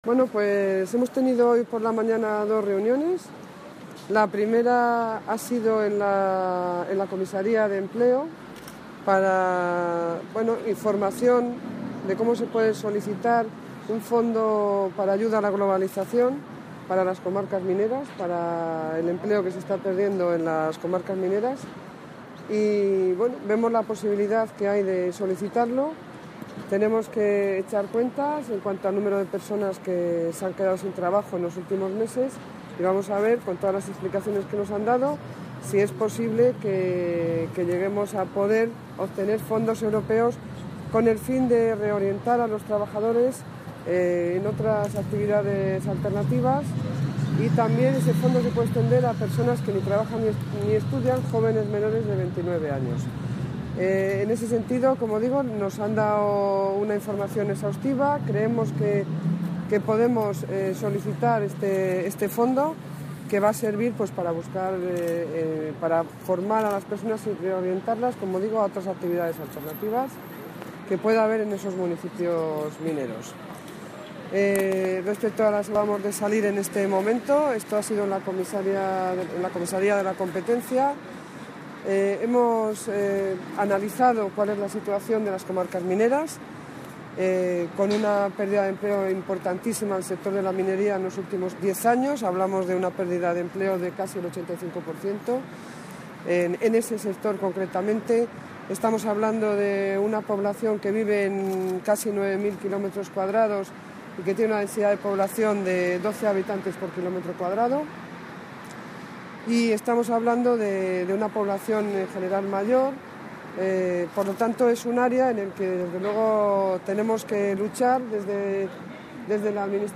Valoraciones de la consejera de Economía y Hacienda, Pilar del Olmo, tras presentar en Bruselas las propuestas de Castilla y León en defensa de la minería del carbón Más información sobre el Fondo Europeo de Adaptación a la Globalización